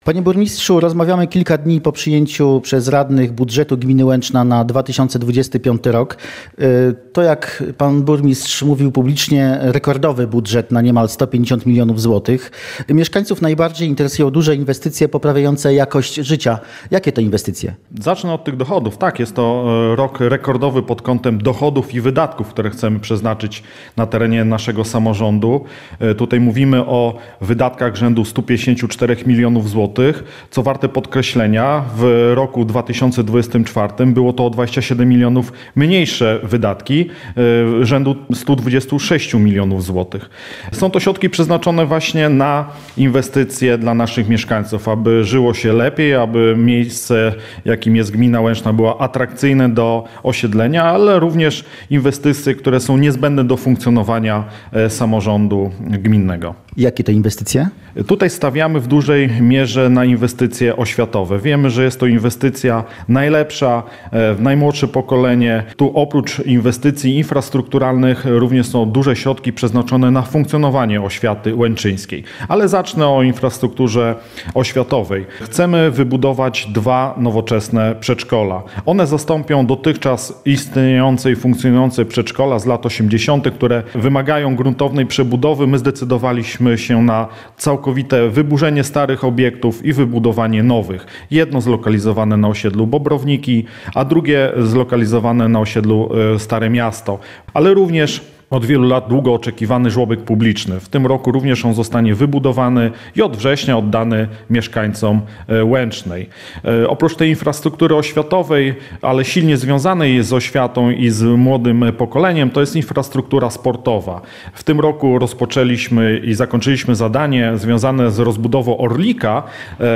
Rozmowa z burmistrzem Łęcznej Leszkiem Włodarskim